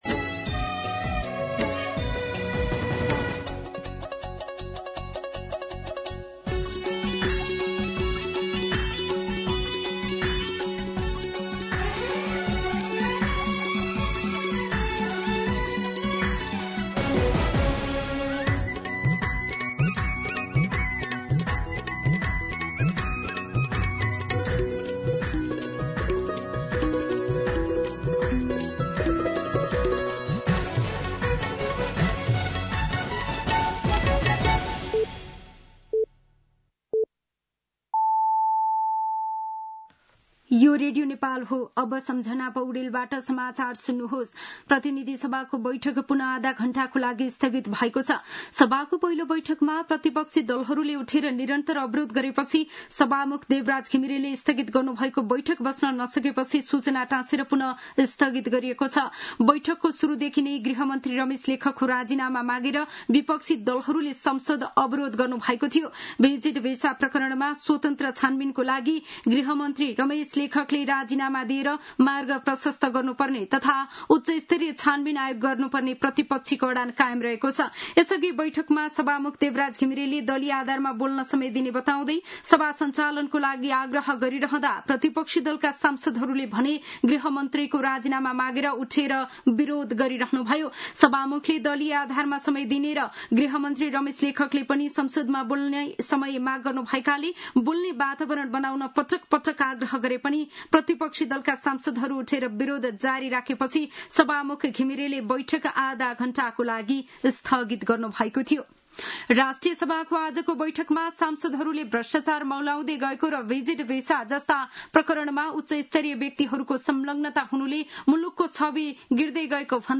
दिउँसो ४ बजेको नेपाली समाचार : १४ जेठ , २०८२
4pm-News-02-14.mp3